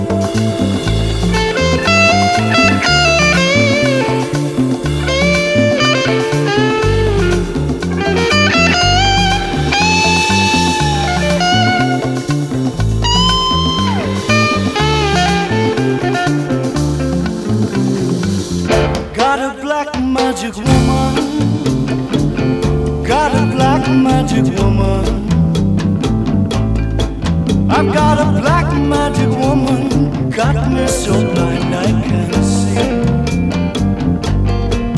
Жанр: Поп музыка / Рок / Джаз